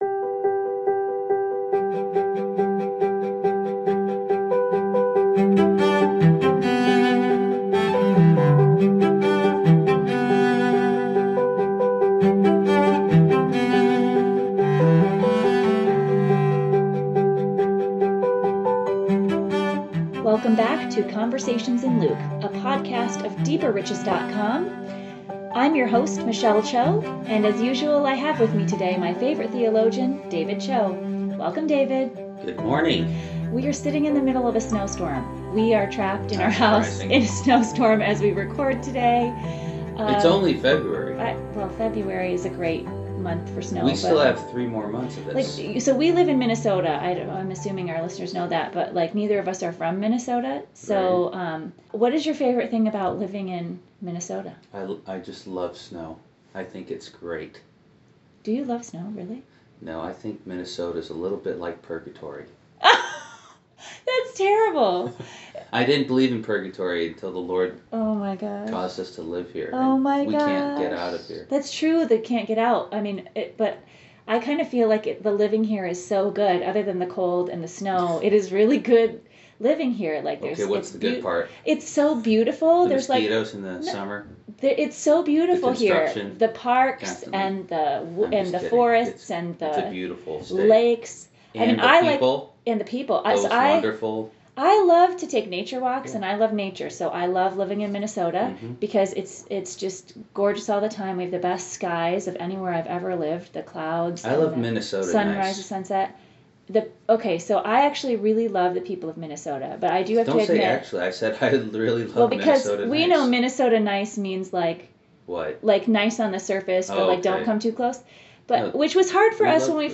Read Luke 9, then listen to the conversation!